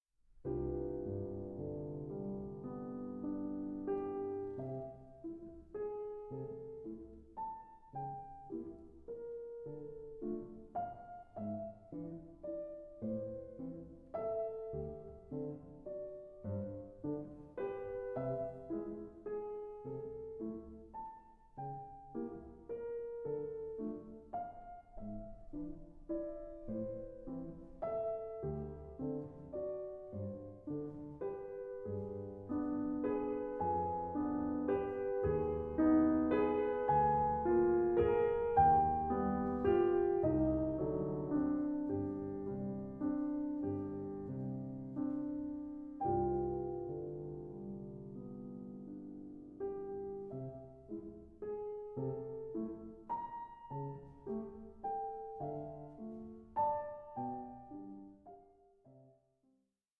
Allegro molto vivace 8:17